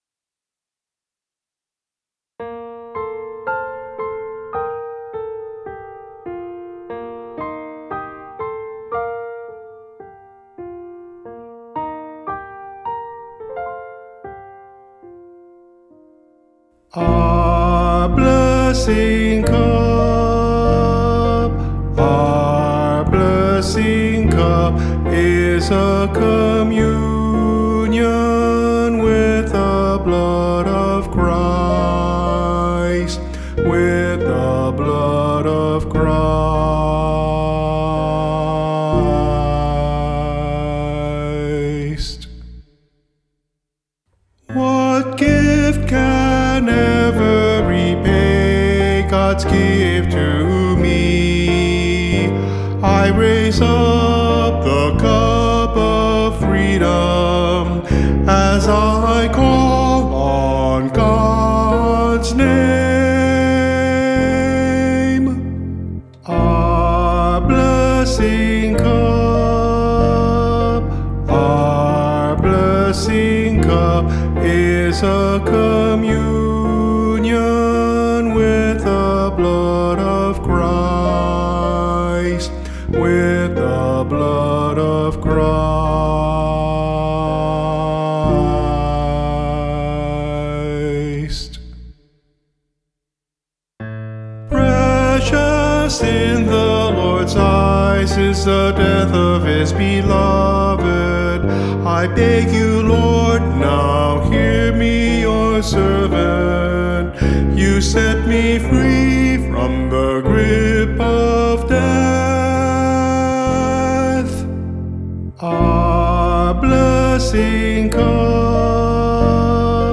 S      A       T       B     Desc.